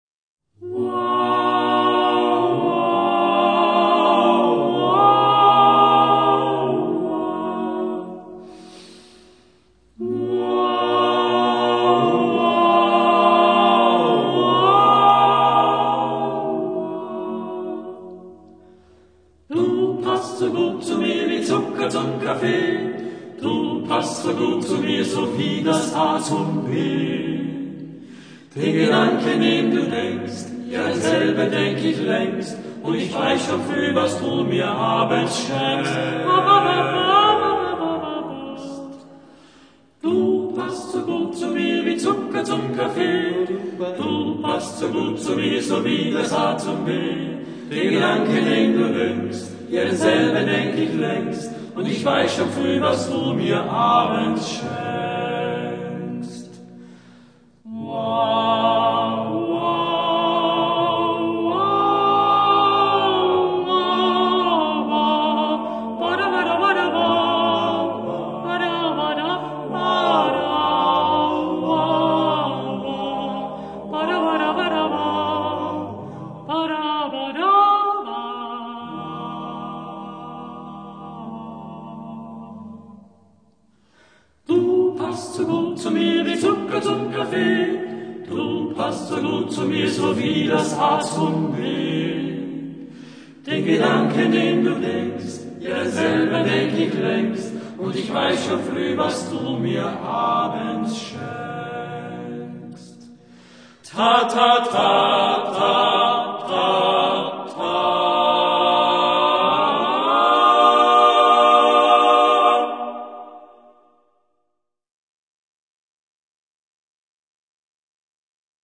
arrangiert für Männerchor.